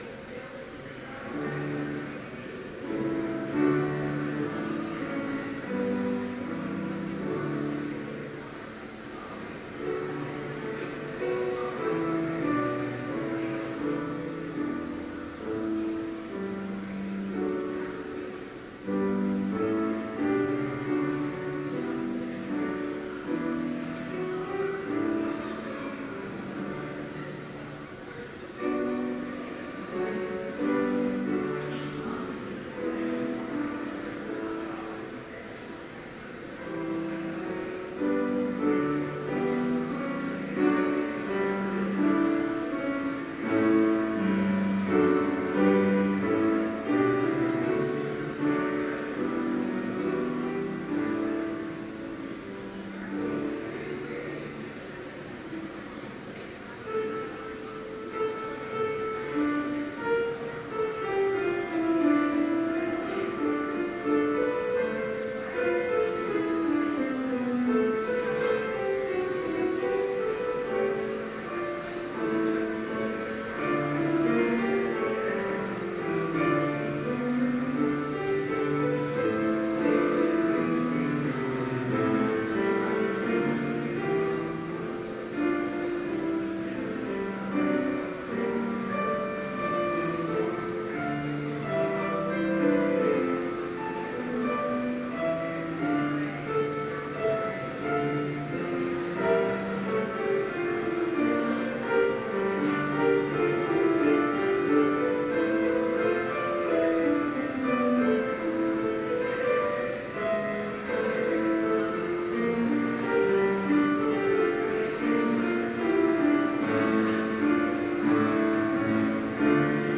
Complete service audio for Chapel - November 9, 2012